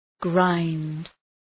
{graınd}